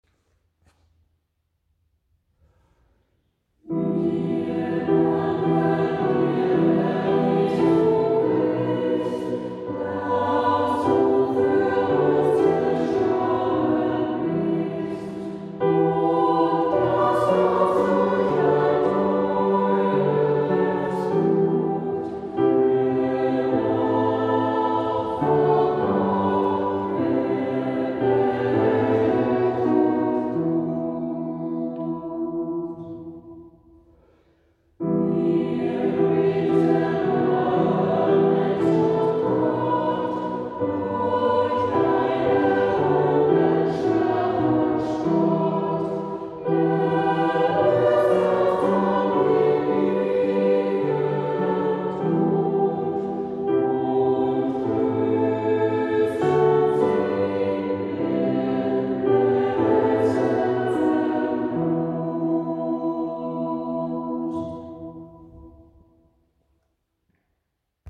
Die Stadtkantorei darf sie nach über zweijähriger, pandemiebedingter Pause endlich wieder mitgestalten.
Hier ist ein kleiner Ausschnitt aus der letzten Chorprobe (“Wir danken dir, Herr Jesu Christ, dass du für uns gestorben bist” von Johann Hermann Schein, 1627):